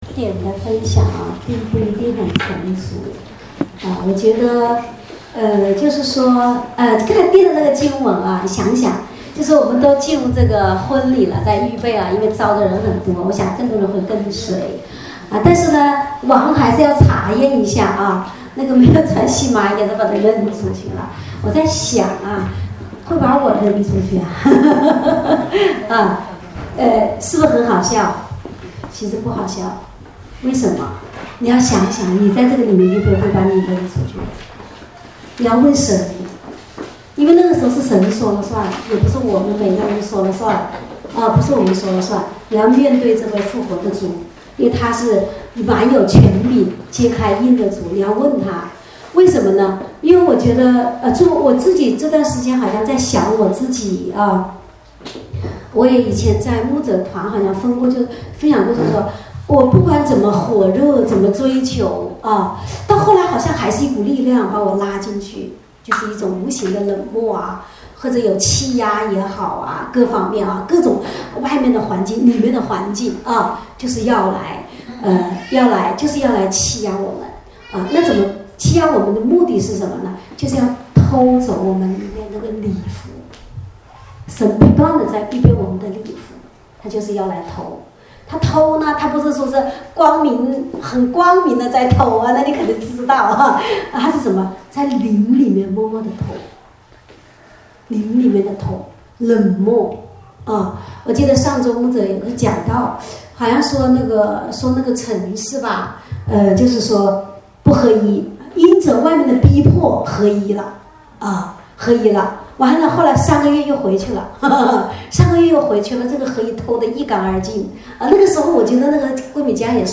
主日恩膏聚会录音（2015-10-11）